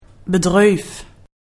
oetspraok / ipa
/bəˈdʀøˑf/